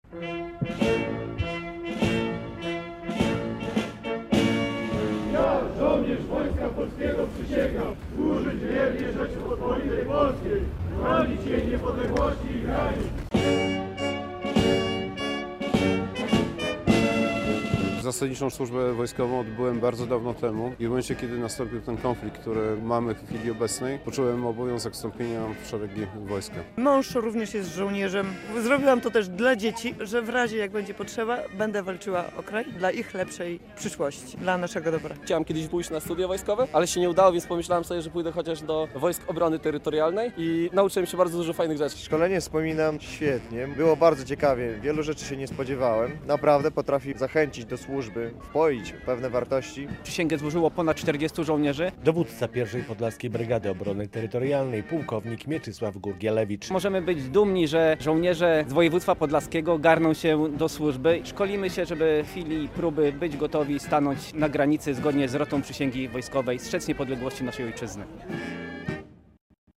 Czterdziestu żołnierzy obrony terytorialnej, po szesnastodniowym szkoleniu podstawowym, złożyło w sobotę (30.11) przysięgę wojskową. Uroczystości, w których wzięli udział także członkowie ich rodzin, odbyły się w Brańsku na głównym placu miasta.